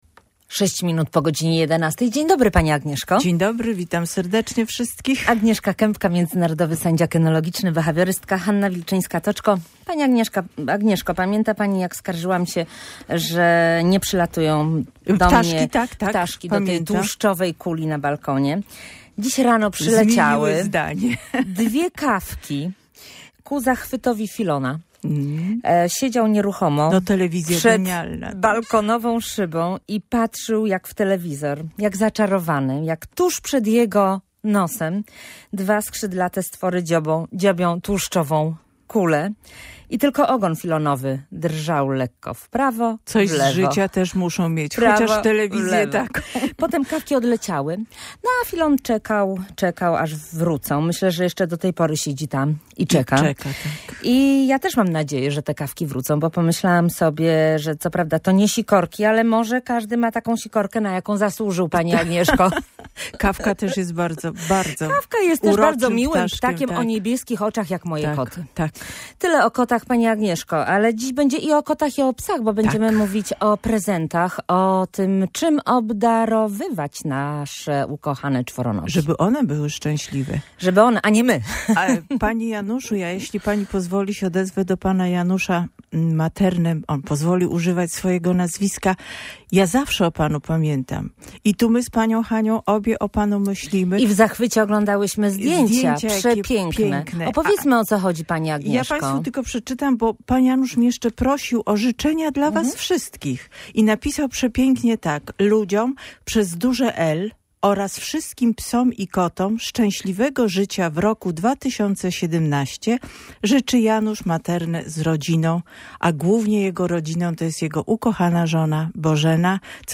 – Prezent pod choinką to sprawa obowiązkowa – podkreśla behawiorystka.